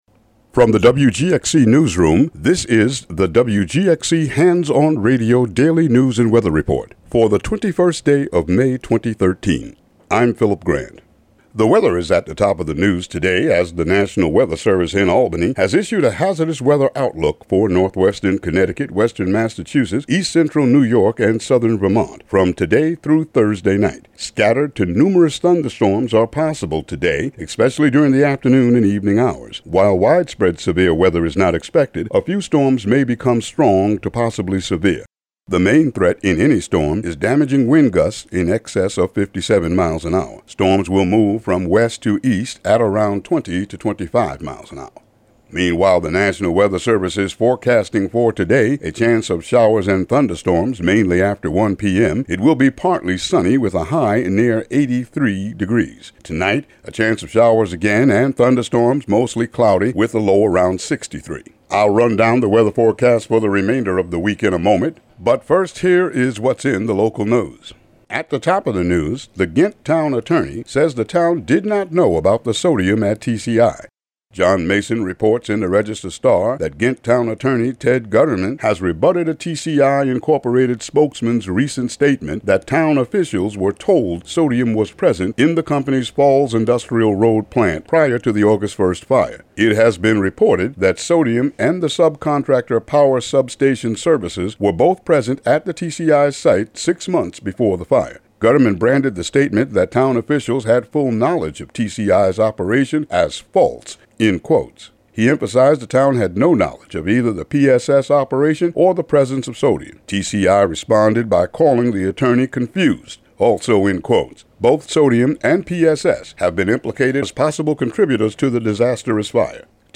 Local news and weather for Tuesday, May 21, 2013.